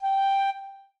flute_g.ogg